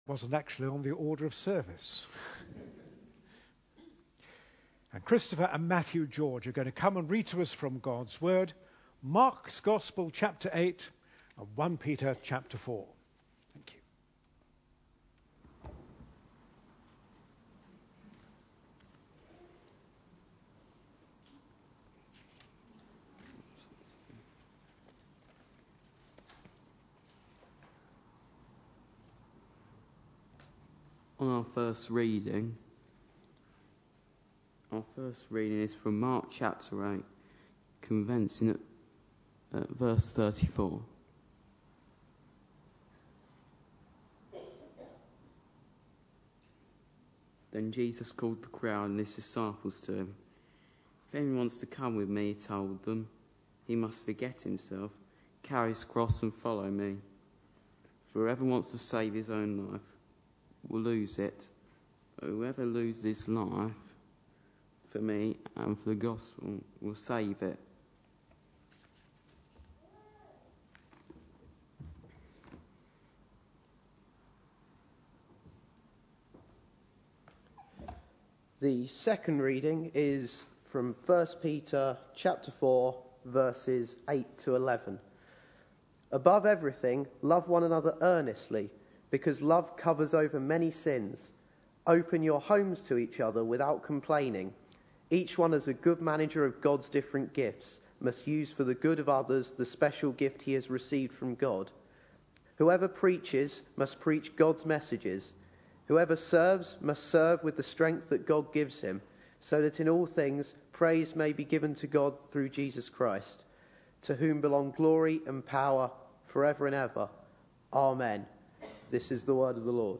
A sermon preached on 14th October, 2012, as part of our The Message of Peter for Today series.